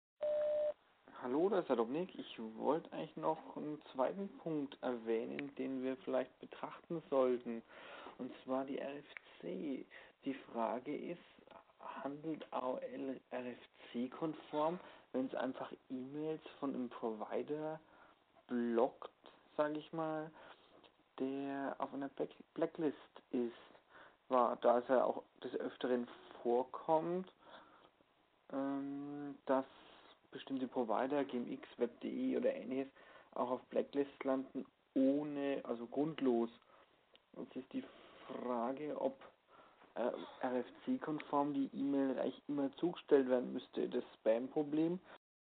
Kommentar via Telefon